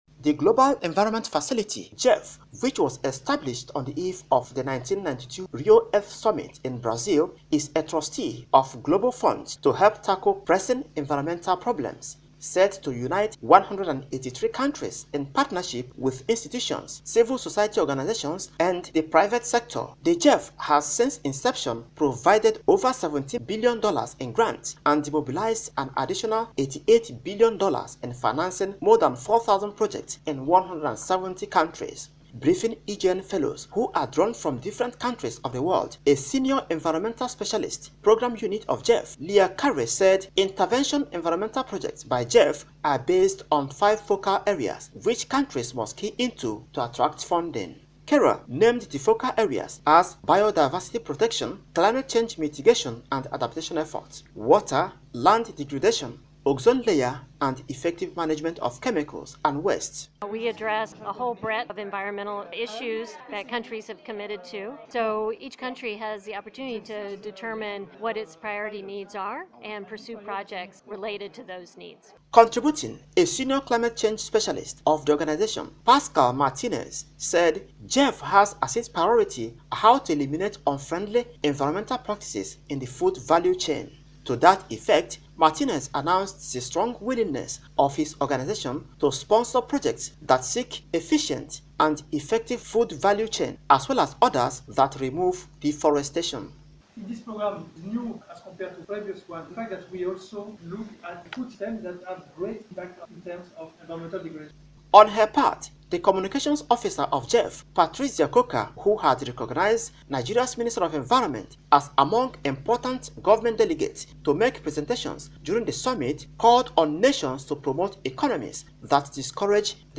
Radio Reports